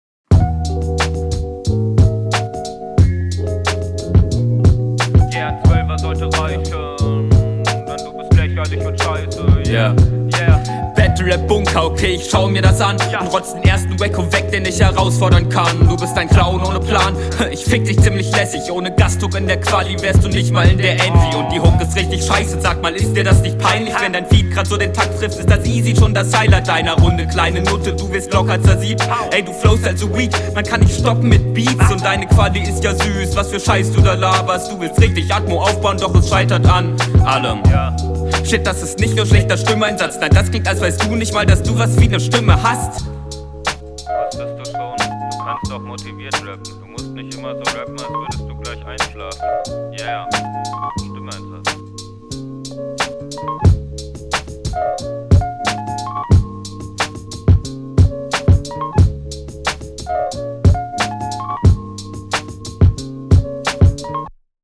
beat is chillig auf jeden, guter pick. du hast ne sehr eigene stimme als rapper, …
Sound ist auch okay, aber noch ausbaufähig.
Smoother Beatpick, stimmlich find ich dich aber nicht fresh drauf.